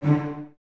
Index of /90_sSampleCDs/Miroslav Vitous - String Ensembles/Cellos/CES Stacc